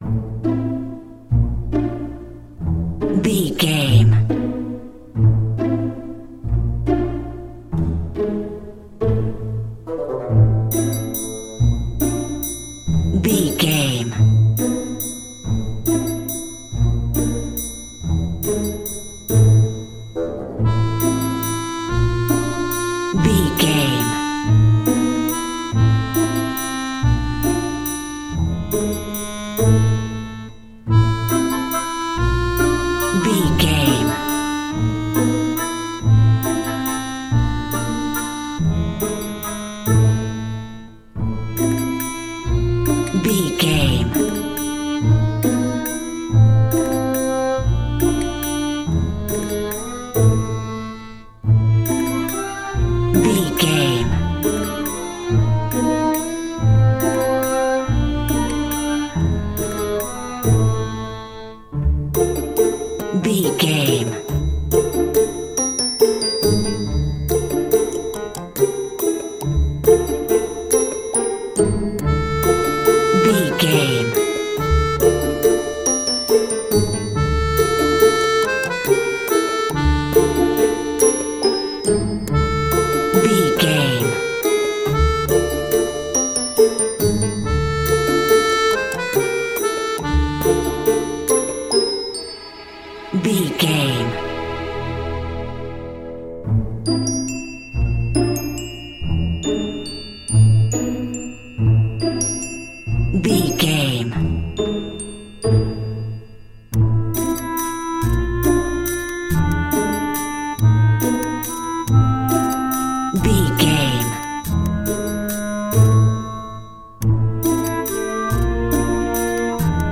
Ionian/Major
orchestra
strings
flute
drums
violin
circus
goofy
comical
cheerful
perky
Light hearted
quirky